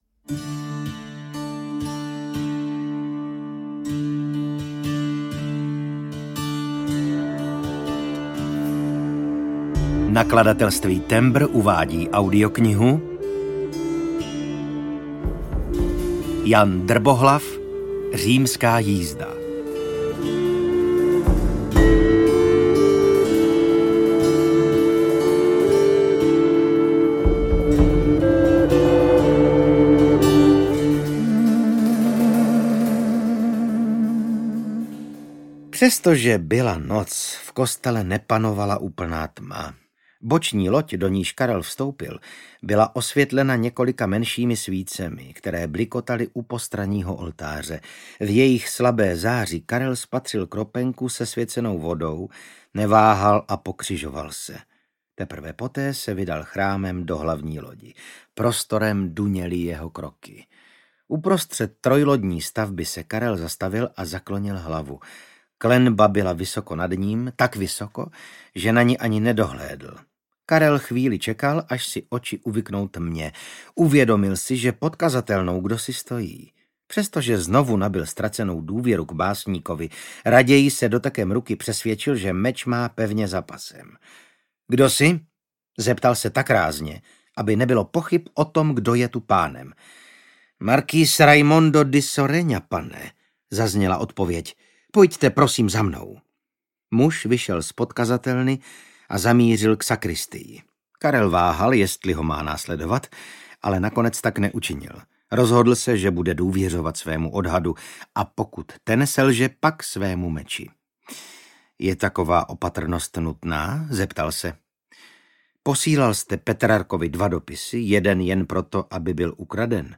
Římská jízda audiokniha
Ukázka z knihy
• InterpretVasil Fridrich
rimska-jizda-audiokniha